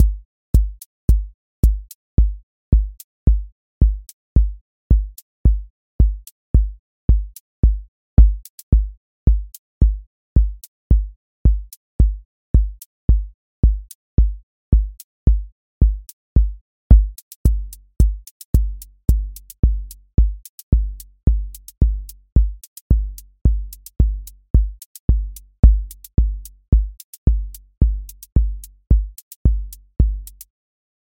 Four on Floor QA Listening Test house Template: four_on_floor April 18, 2026 ← Back to all listening tests Audio Four on Floor
voice_kick_808 voice_hat_rimshot voice_sub_pulse